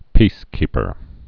(pēskēpər)